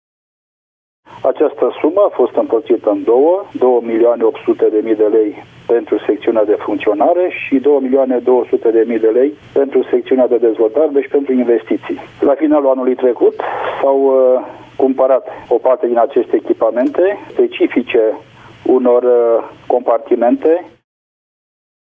Fondurile, adică cinci milioane de lei, au fost alocate de Guvern, la finalul anului trecut, a declarat primarul Virgil Popa:
Primar-Virgil-Popa-spital-Sacele.mp3